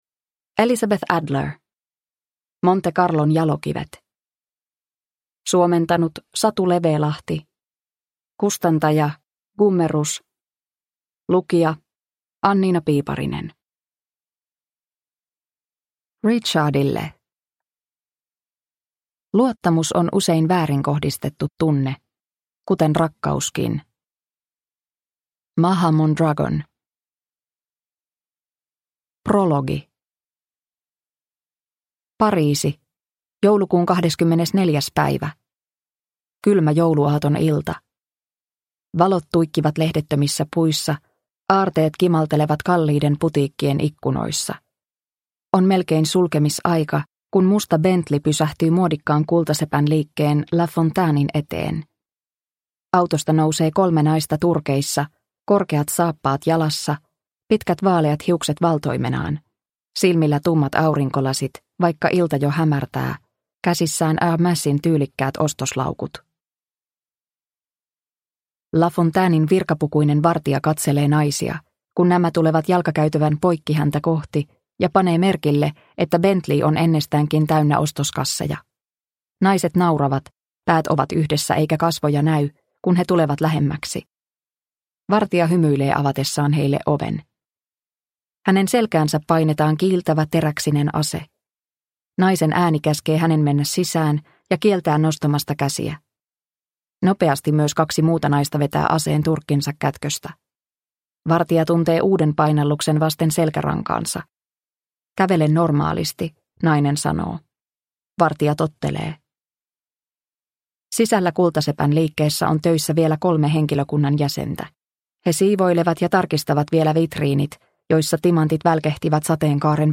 Monte Carlon jalokivet – Ljudbok – Laddas ner